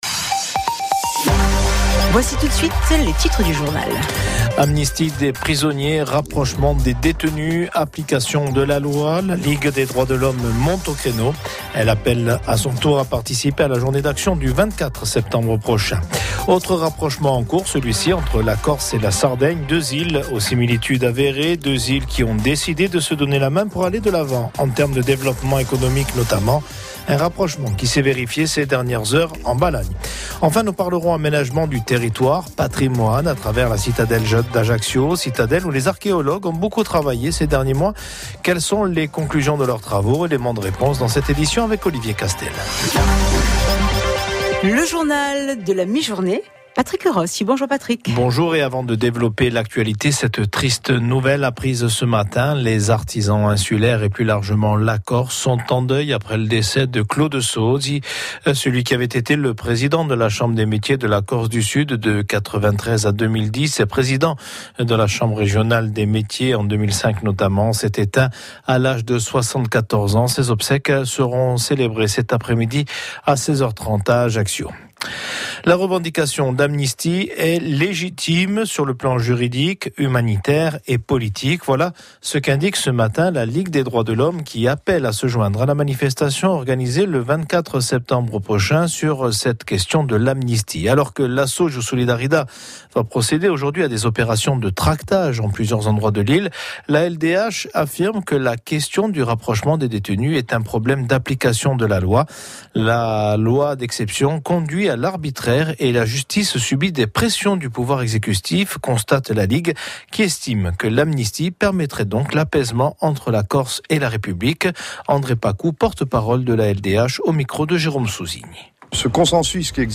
RCFM - Journal de 12h - 17/09